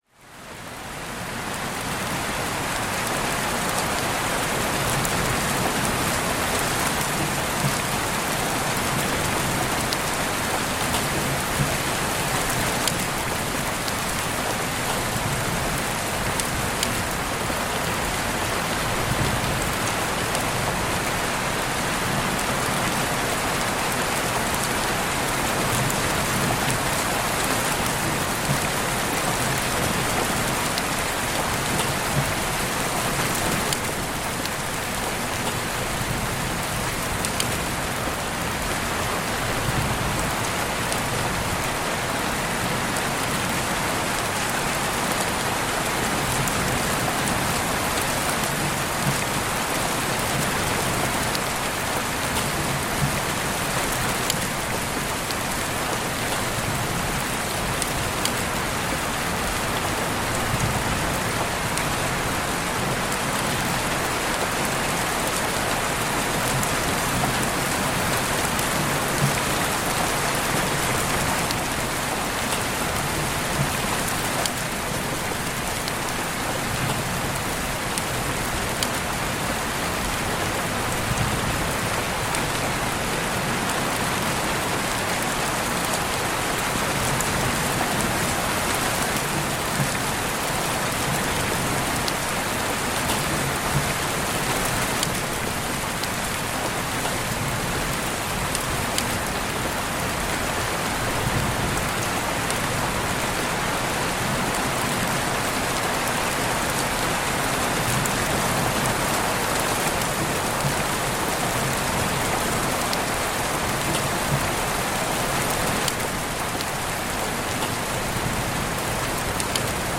Tormenta de Lluvia y Ruido Marrón para Enfoque y un Sueño que Repara
Sonidos de Lluvia, Lluvia para Dormir, Lluvia Relajante, Lluvia Suave, Lluvia Para Calmar